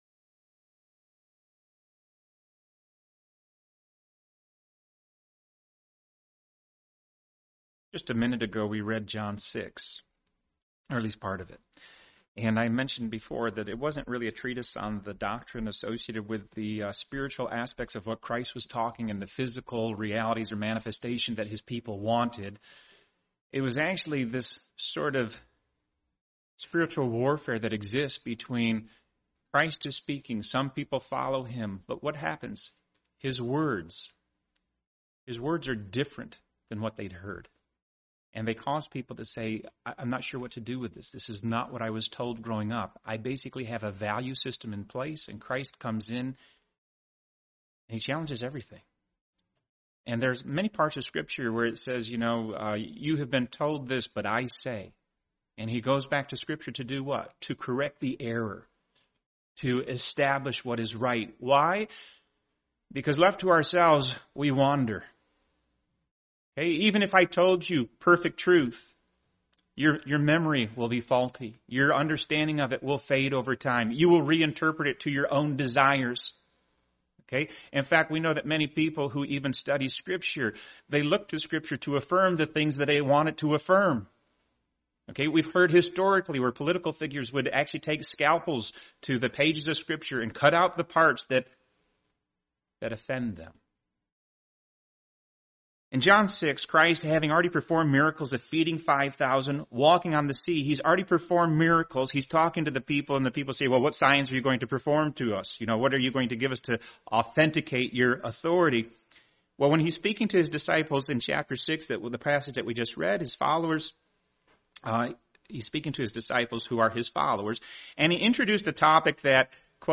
Part of the Topical series, preached at a Morning Service service.